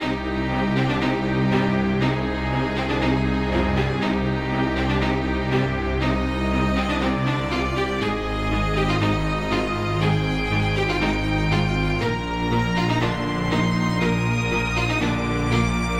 小号合奏101
描述：8小节的小号合奏曲。
它实际上是调式为。Ab大调（4个调）。
标签： 120 bpm Orchestral Loops Brass Loops 2.69 MB wav Key : A
声道立体声